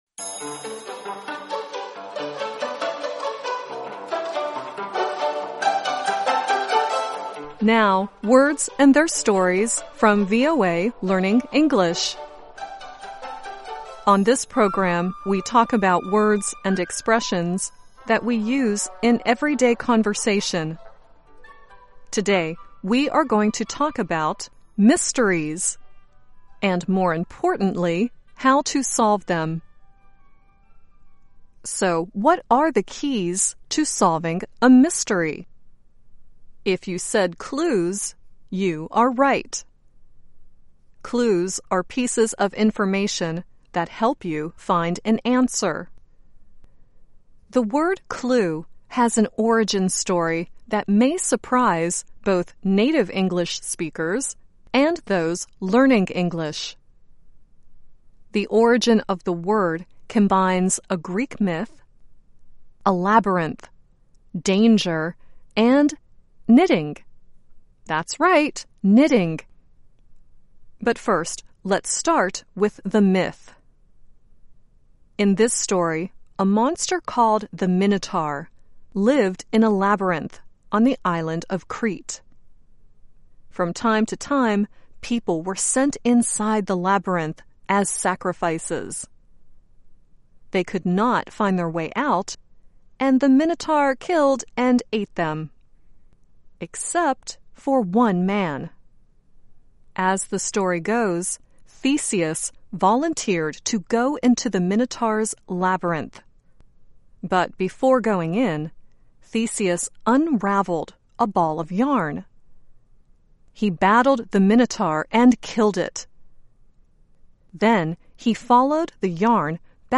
The song at the end is Royksopp singing "You Don't Have a Clue."